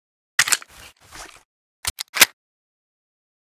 grach_reload.ogg